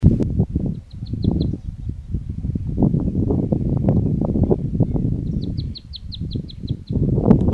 Chaco Earthcreeper (Tarphonomus certhioides)
Life Stage: Adult
Detailed location: Laguna Guatraché
Condition: Wild
Certainty: Observed, Recorded vocal